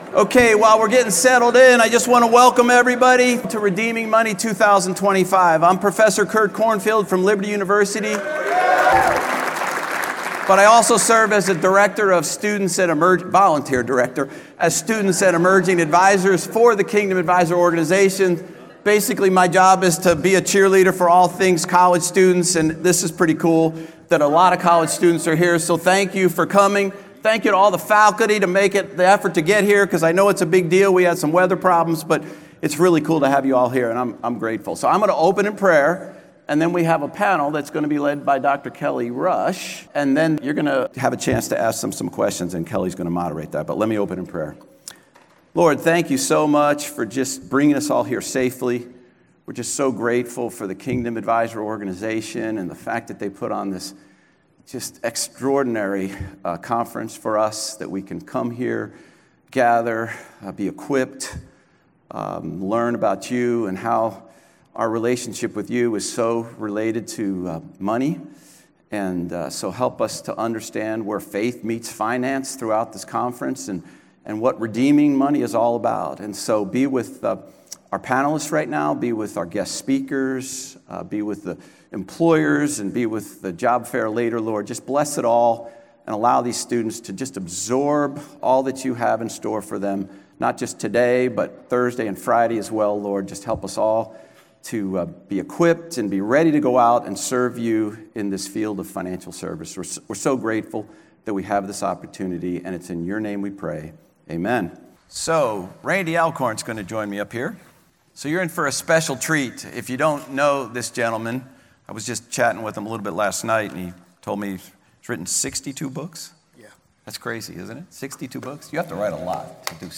Riches, Our Hearts, and Eternal Treasures: Student Session at the Kingdom Advisors Conference
university_student_session_edited.mp3